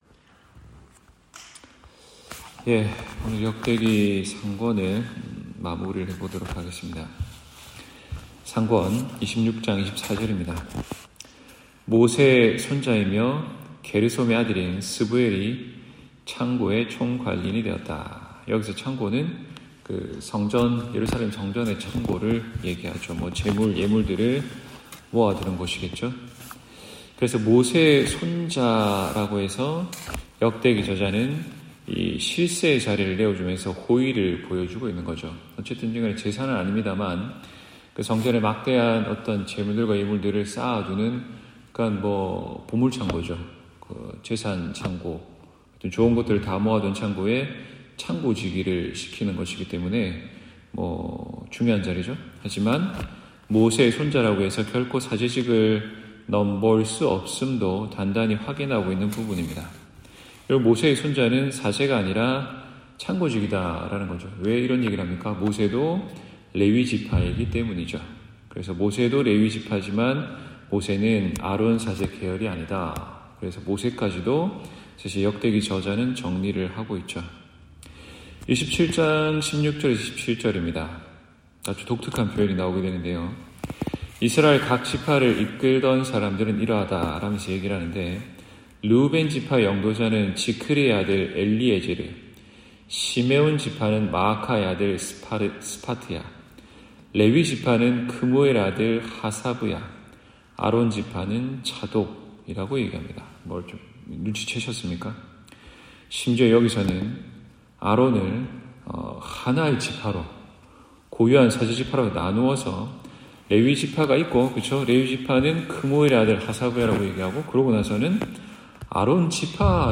성서공부135-역대기상 (2024년 11월20일 수요일)